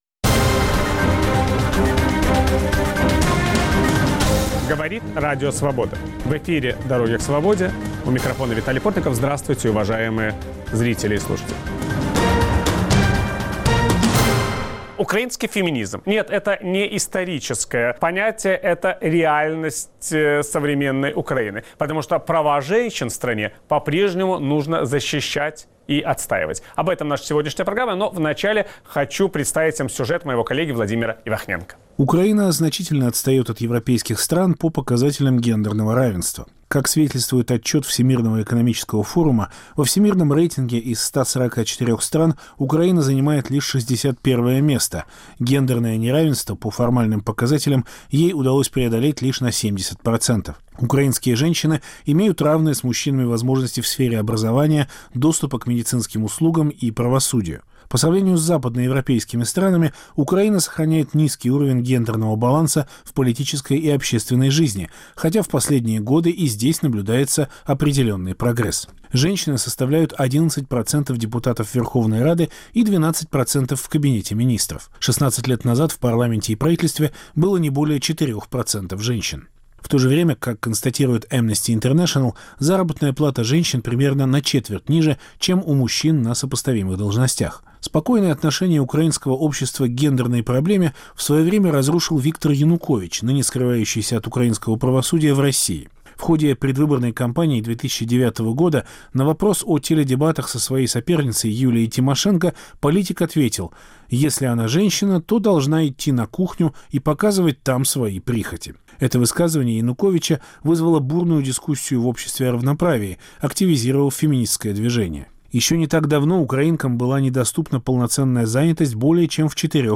Виталий Портников беседует